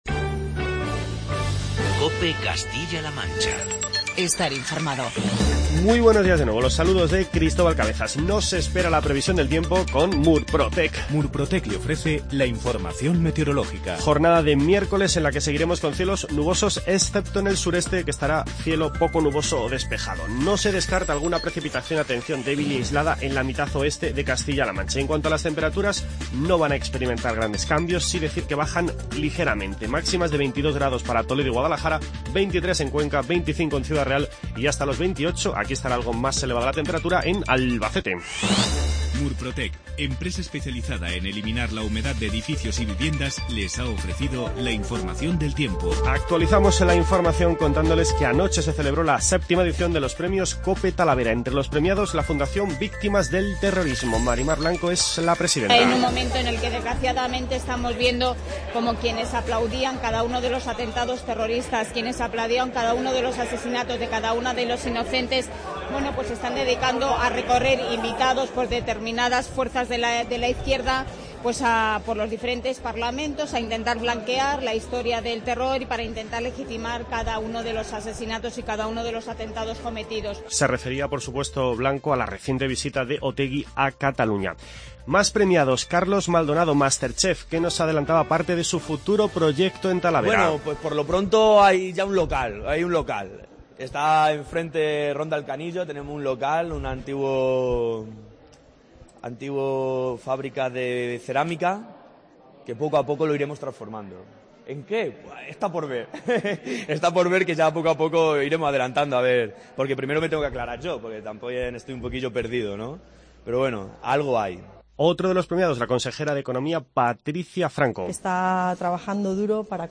Informativo regional
Escuchamos los sonidos más destacados de la VII edición de la gala de los Premios COPE Talavera celebrada anoche en el Teatro Victoria de la ciudad de la cerámica.